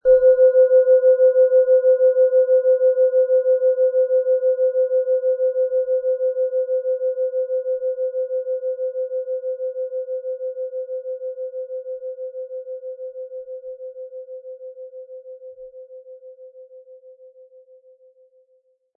Tibetische Kopf-Herz- und Bauch-Klangschale, Ø 11,9 cm, 260-320 Gramm, mit Klöppel
Um den Originalton der Schale anzuhören, gehen Sie bitte zu unserer Klangaufnahme unter dem Produktbild.
Sanftes Anspielen wird aus Ihrer bestellten Klangschale mit dem beigelegten Klöppel feine Töne zaubern.